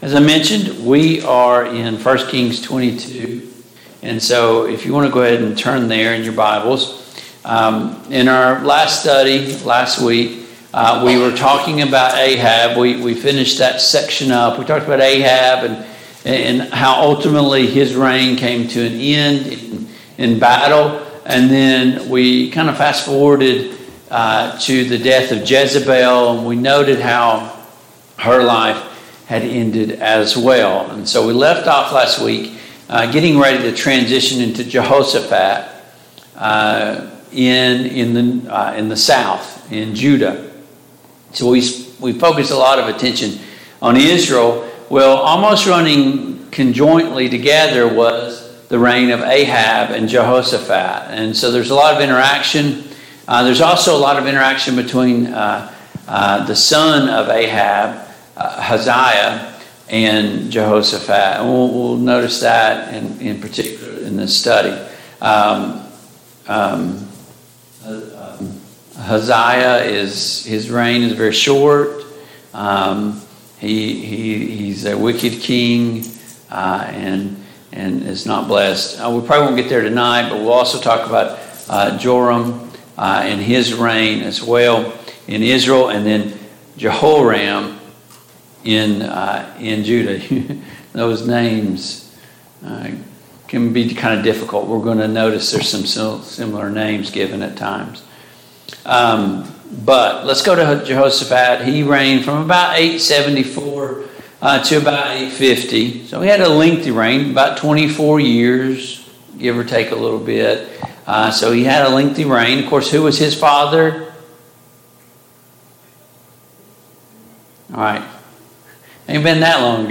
The Kings of Israel Passage: 1 Kings 21, 1 Kings 22 Service Type: Mid-Week Bible Study Download Files Notes « Speak Lord for your servant hears 8.